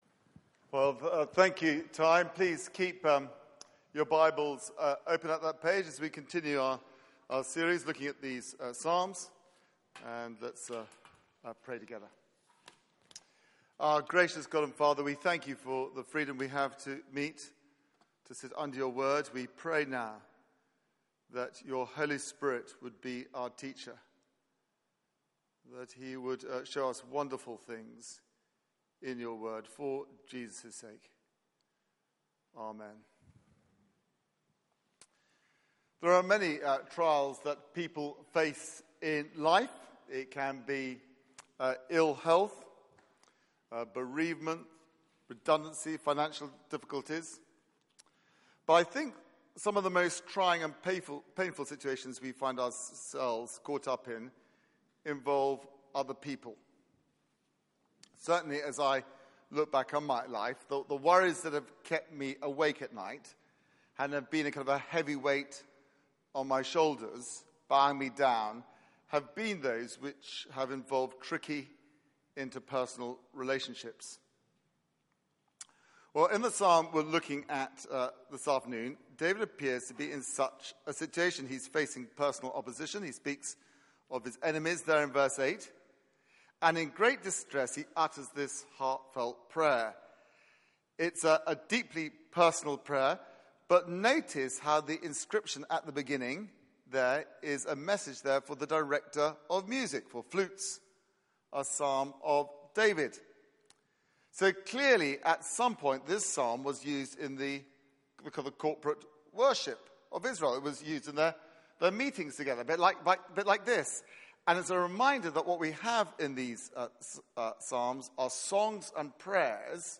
Media for 4pm Service on Sun 07th Aug 2016 16:00 Speaker
Sermon Search the media library There are recordings here going back several years.